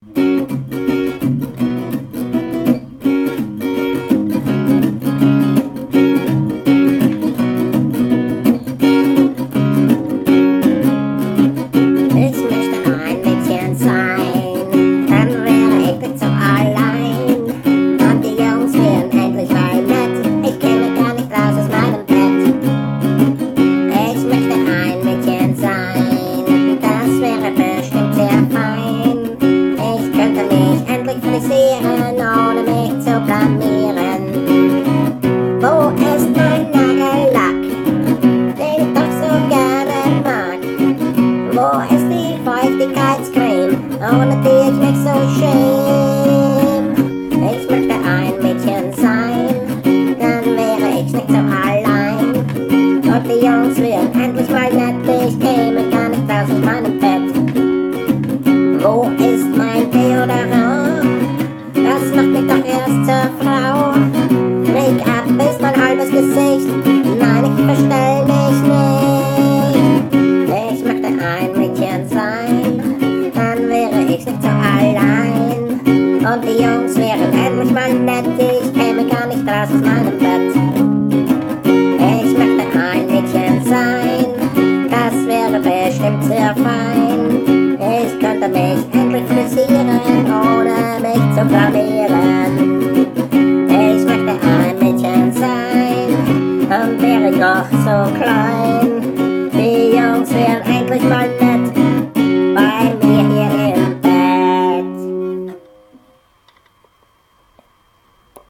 Genre: Freie Musik - Austropop